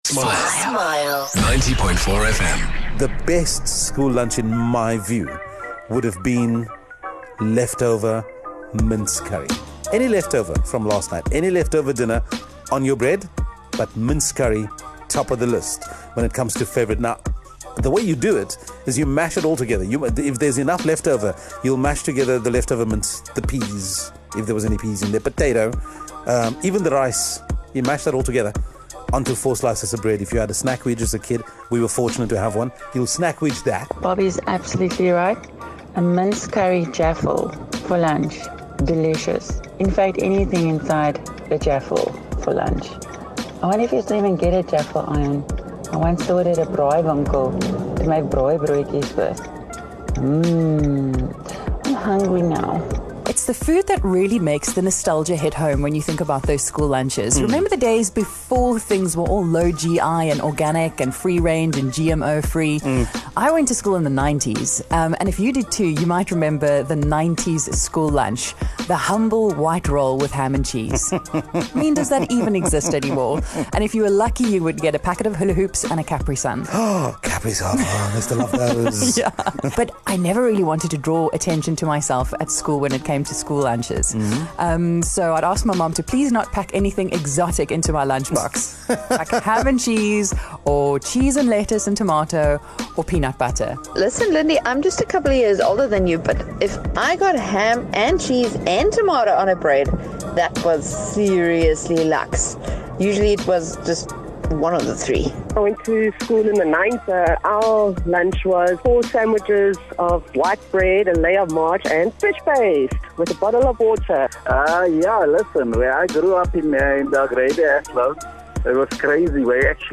Callers told us about what their lunchboxes used to look like when they were in school. They've changed quite a bit with what you give your kids these days.